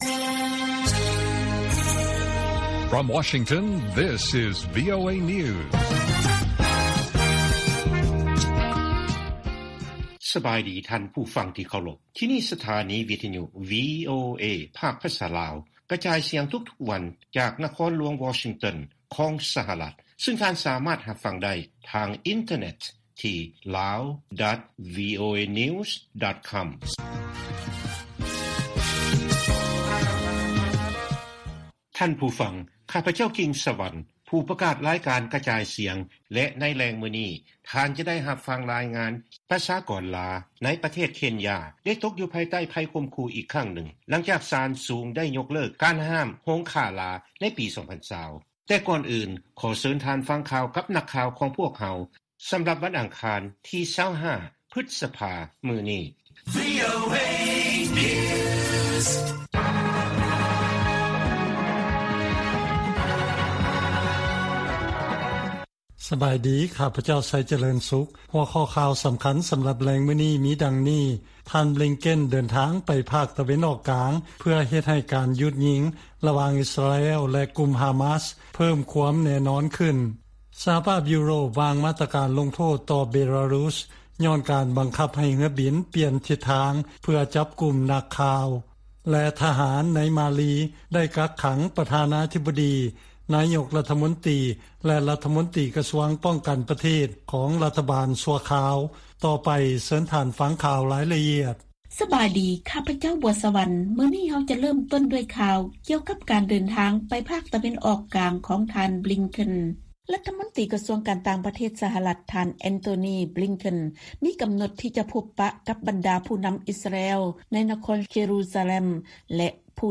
ວີໂອເອພາກພາສາລາວກະຈາຍສຽງທຸກໆວັນ. ຫົວຂໍ້ຂ່າວສໍາຄັນໃນມື້ນີ້ມີ: 1) ທ່ານນາງ ອອງ ຊານ ຊູ ຈີ ປາກົດຕົວຕໍ່ສານເປັນຄັ້ງທຳອິດນັບຕັ້ງແຕ່ມີການກໍ່ລັດຖະປະຫານ.